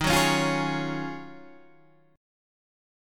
Ebm11 chord